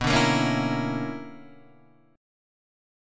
B Minor Major 11th